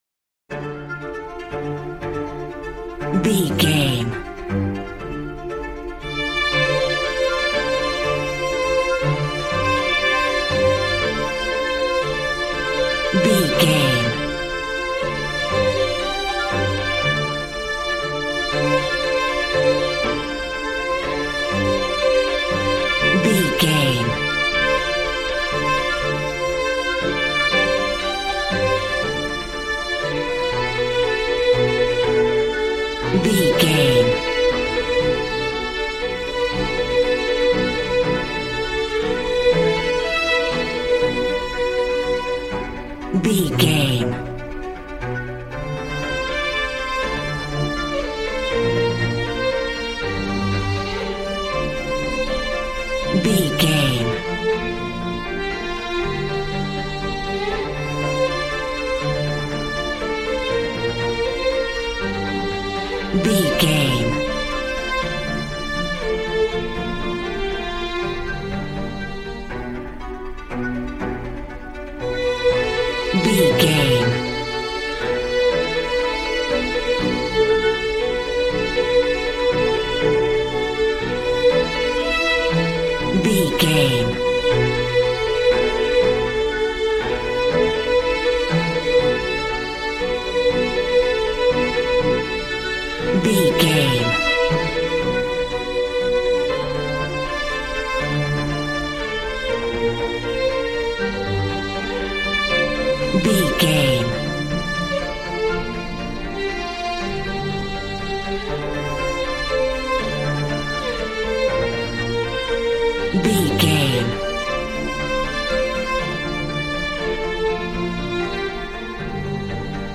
Regal and romantic, a classy piece of classical music.
Aeolian/Minor
regal
cello
violin
brass